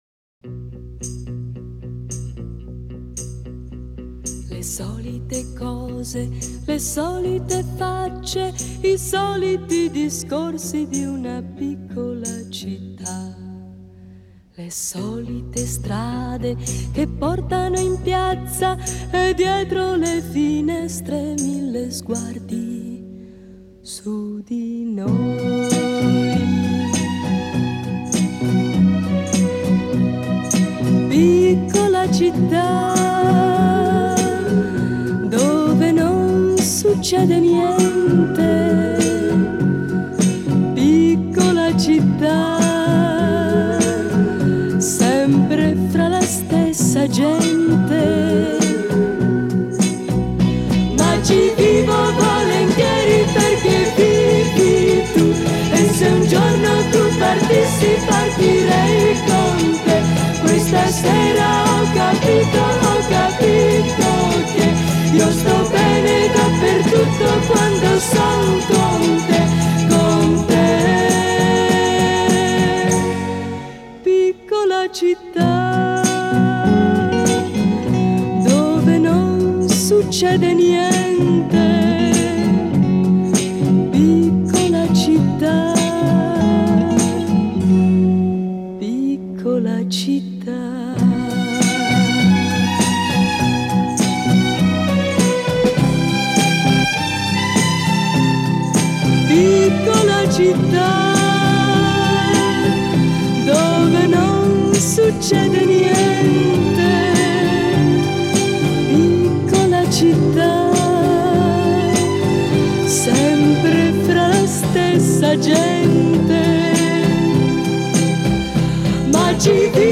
Genre: Pop, oldies